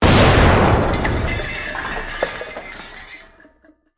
combat / weapons / rocket / metal2.ogg
metal2.ogg